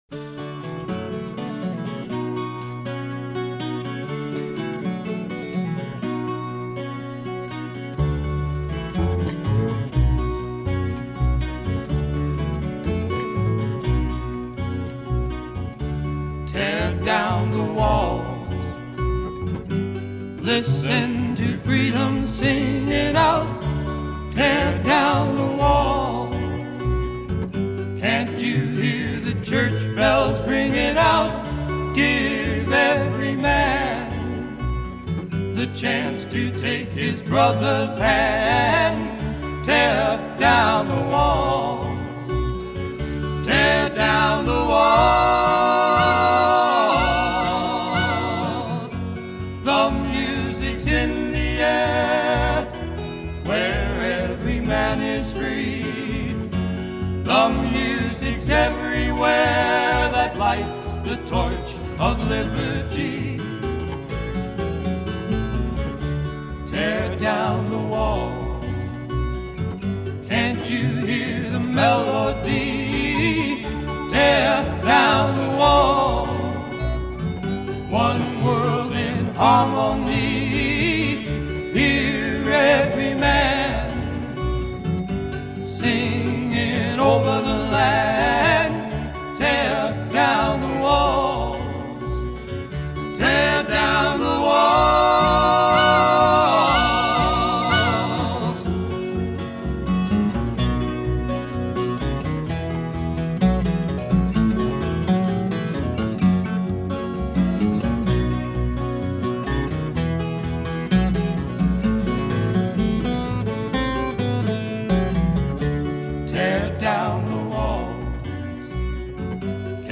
12 string
And, oh, it was a mighty sound.